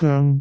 speech
cantonese
syllable
pronunciation